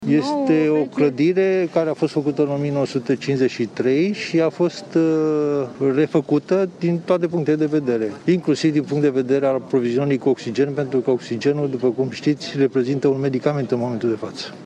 Clădirea unde a avut loc incendiul a fost construită în 1953 , dar a fost renovată complet, spune fostul manager de la Institutul Național ”Matei Balș”, Adrian Streinu Cercel:
29ian-11-Cercel-despre-spital.mp3